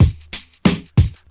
• 93 Bpm Drum Loop Sample E Key.wav
Free drum beat - kick tuned to the E note. Loudest frequency: 1893Hz
93-bpm-drum-loop-sample-e-key-256.wav